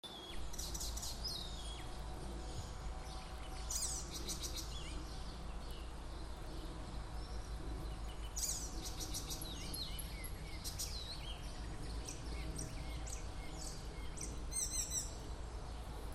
пересмешка, Hippolais icterina
Administratīvā teritorijaJūrmala
СтатусПоёт